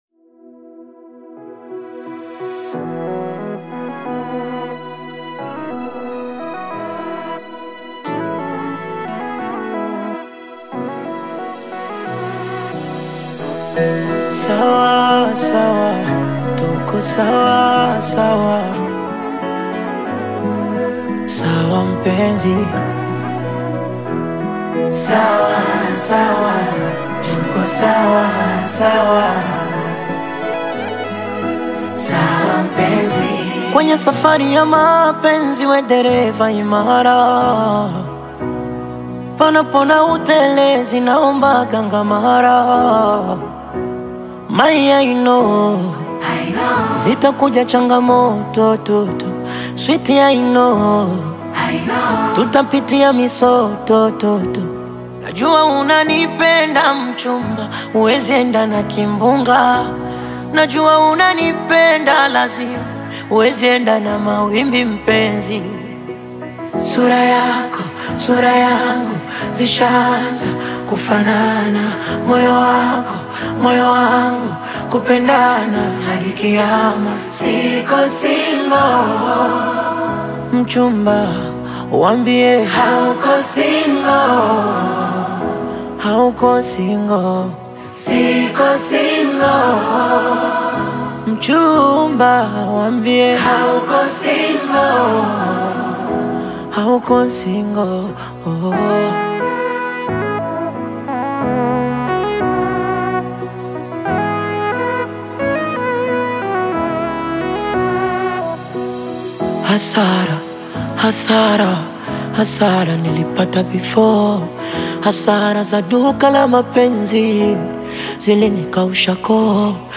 is a soulful acoustic-driven project
stripped-back arrangements and emotive vocals
Recorded with intimate acoustic production
Genre: Accoustic